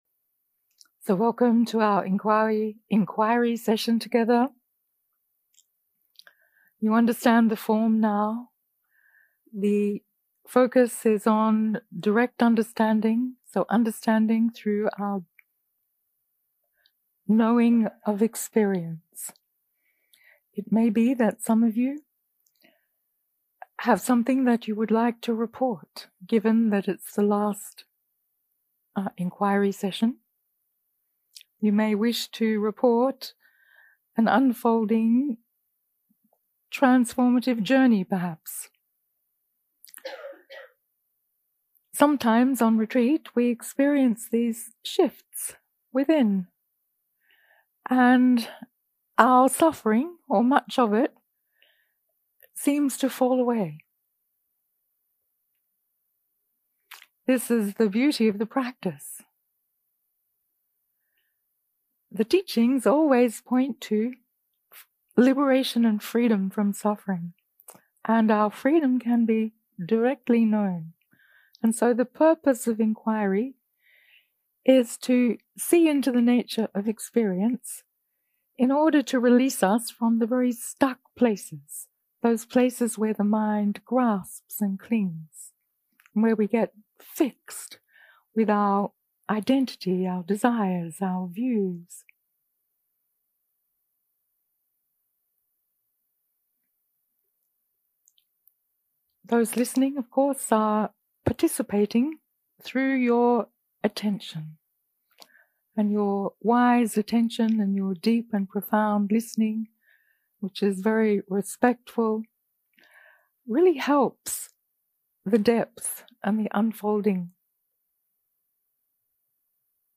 0:00 0:00 סוג ההקלטה: Dharma type: Inquiry שפת ההקלטה: Dharma talk language: English